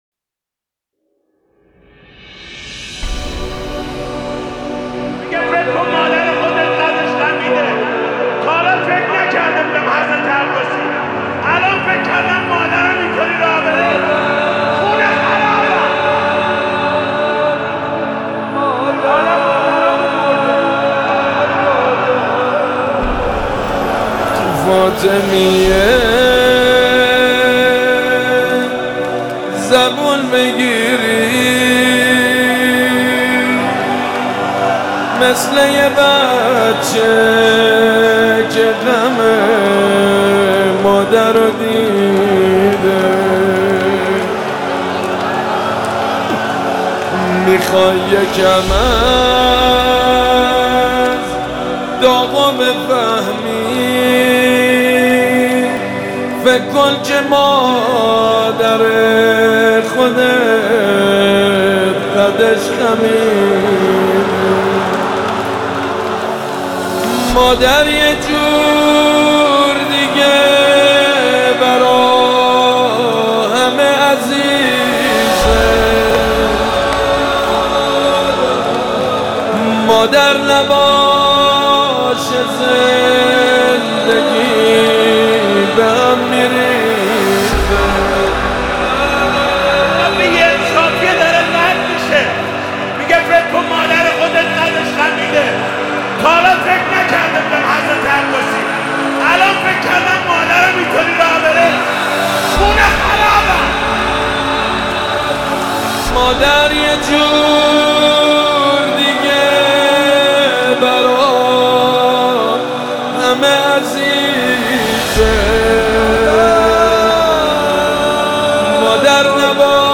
🎧 بشنویم | به مناسبت ایام فاطمیه